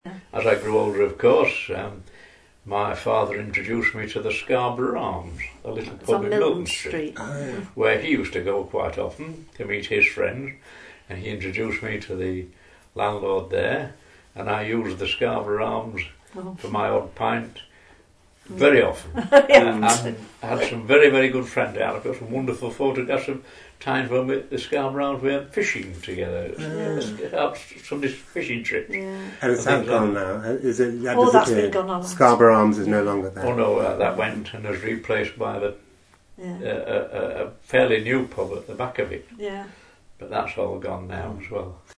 In these audio clips below, past and present residents recollect the names of some of these ‘disappeared’ pubs, and tell some stories about what used to go on…